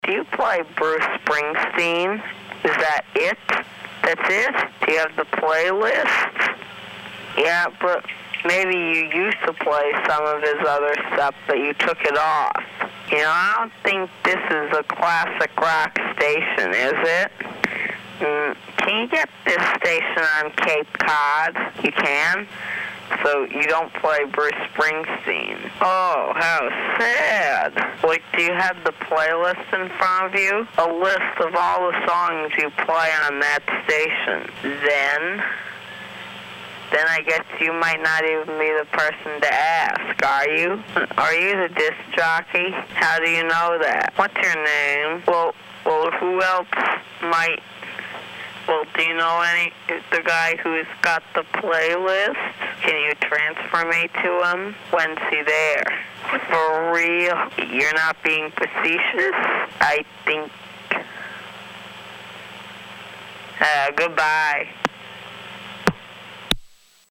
Odd Springsteen fan Q: "goodbye" (click)